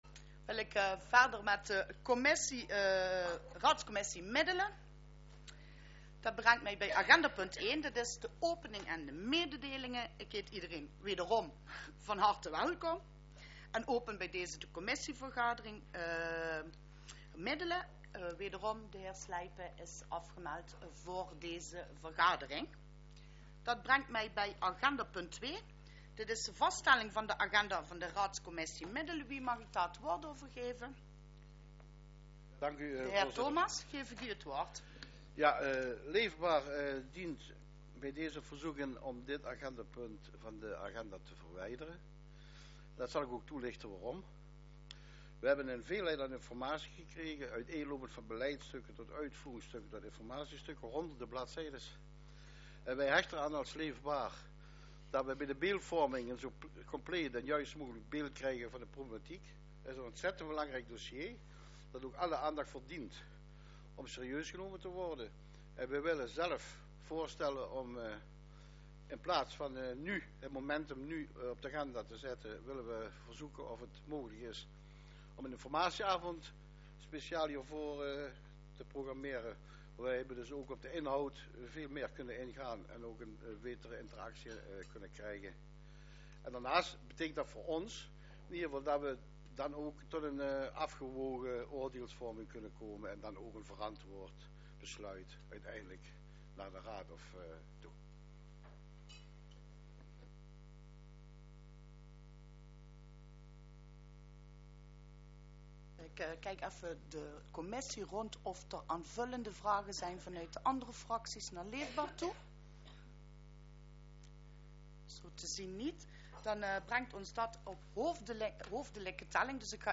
Locatie Raadszaal Toelichting Deze vergadering begint aansluitend aan de vergadering van de Raadscommissie BZ die om 19.00 uur start.